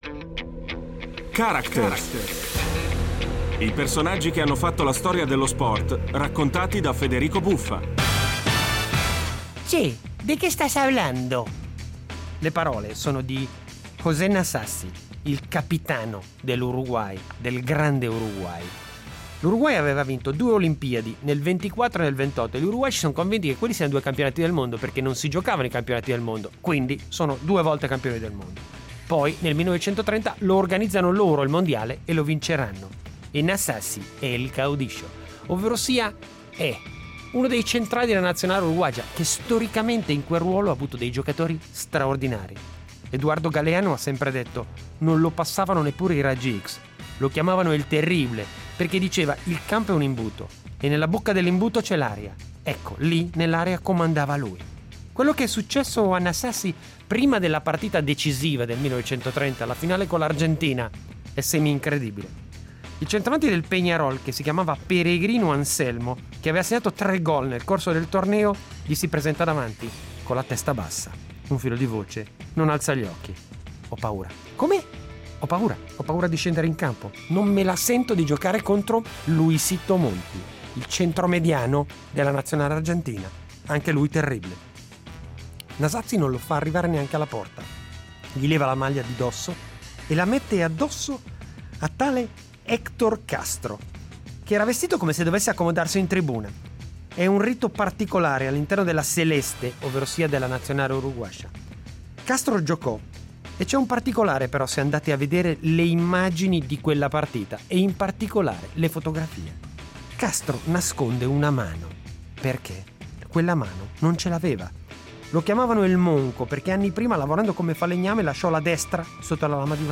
L'incredibile vicenda della leggenda uruguaiana Héctor Castro e il suo Uruguay campione del mondo ai Mondiali casalinghi del 1930 raccontata da Federico Buffa.